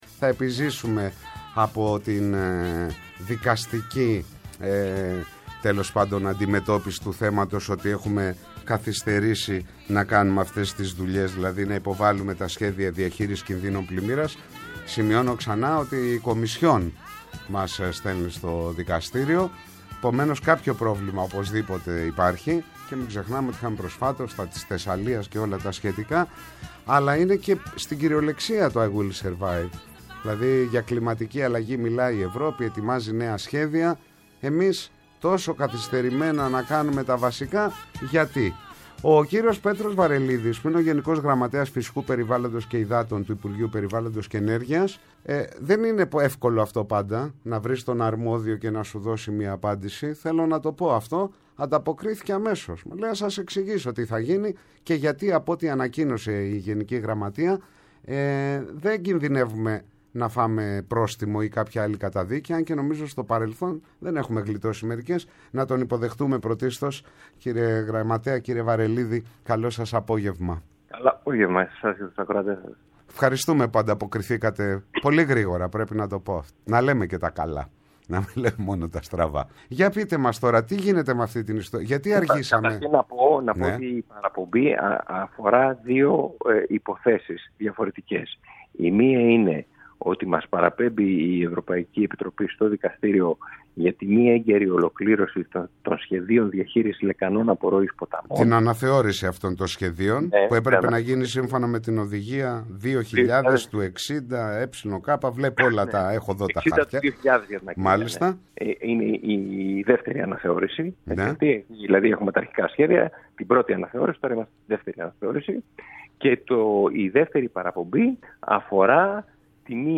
Ο ΓΓ του Υπουργείου Περιβάλλοντος στο Πρώτο Πρόγραμμα | 14.03.2024